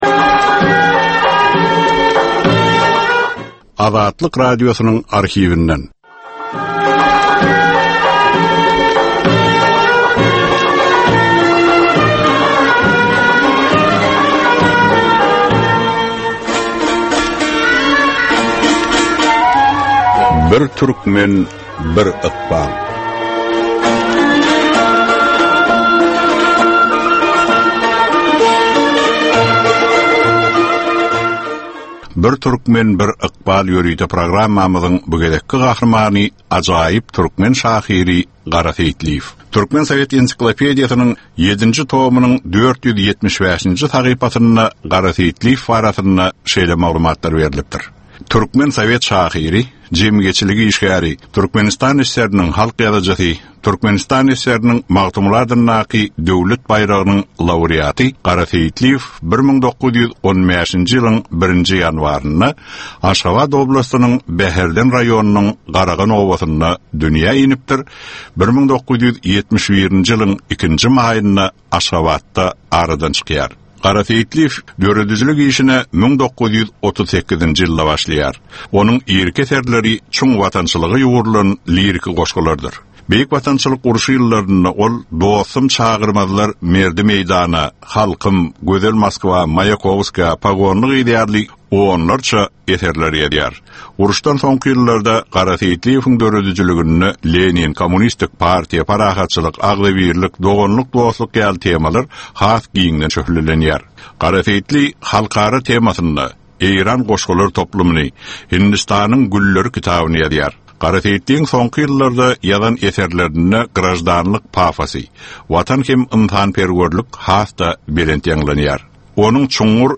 Türkmenistan we türkmen halky bilen ykbaly baglanyşykly görnükli şahsyýetleriň ömri we işi barada ýörite gepleşik. Bu gepleşikde gürrüňi edilýän gahrymanyň ömri we işi barada giňişleýin arhiw materiallary, dürli kärdäki adamlaryň, synçylaryň, bilermenleriň, žurnalistleriň we ýazyjy-sahyrlaryň pikirleri, ýatlamalary we maglumatlary berilýär.